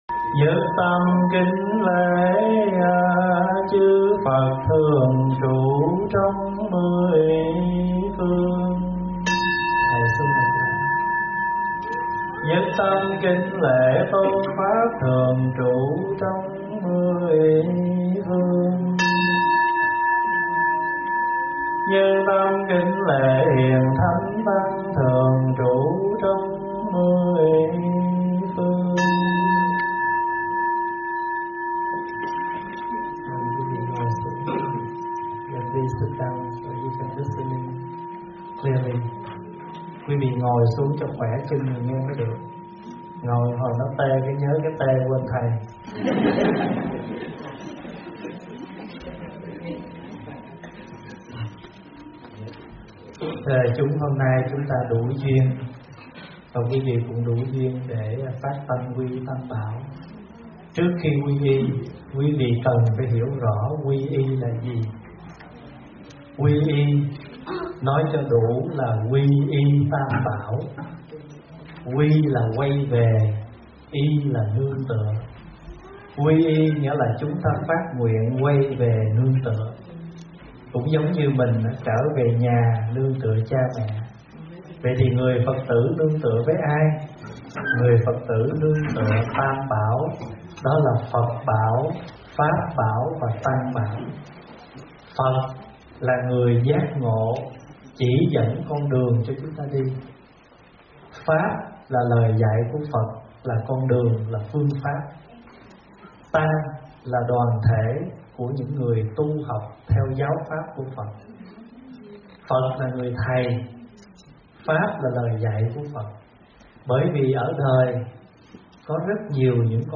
Phật pháp vấn đáp 2016
giảng tại Rheine, Germany ngày 15 tháng 3 năm 2016